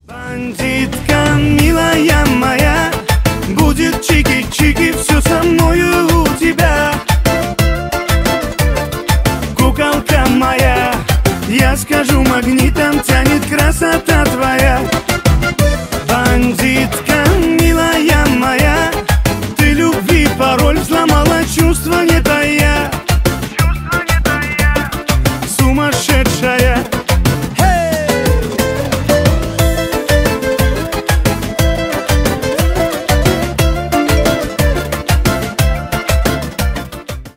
кавказские
поп